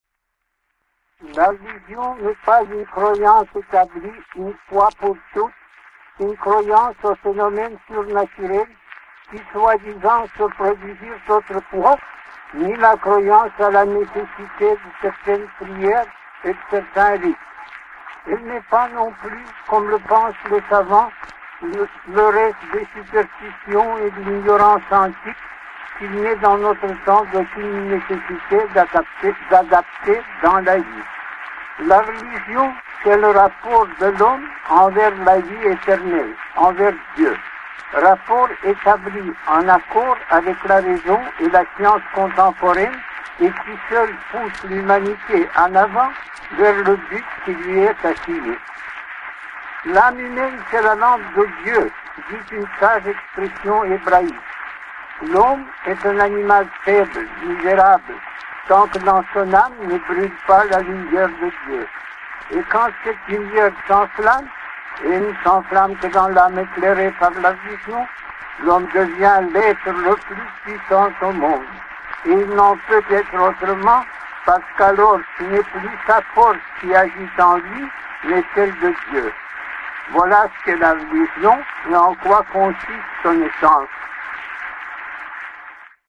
Leo Tolstoy - Voix original